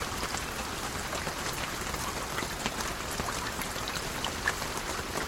rain4.ogg